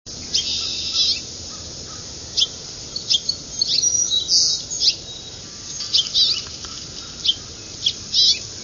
finch_house_chrps_699_mp3.wav